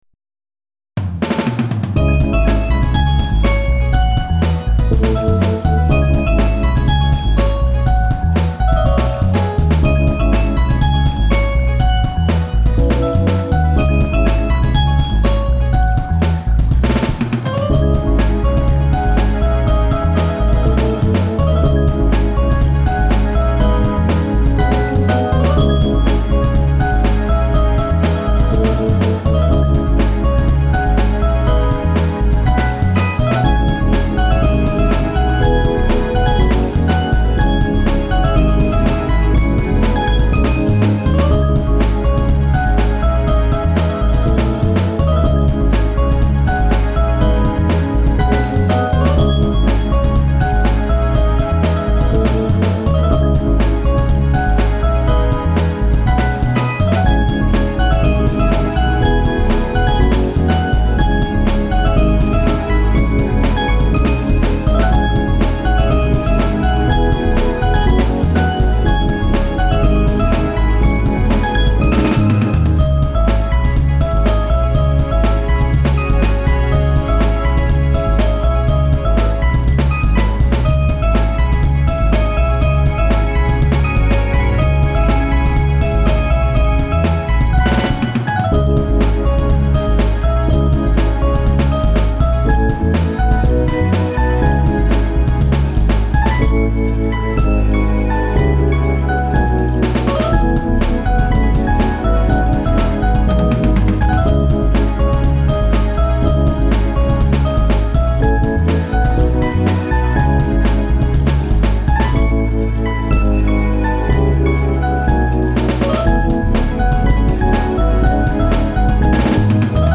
ひっそりと静まり返った夜の森。遠くから誰かの足音が聞こえる・・・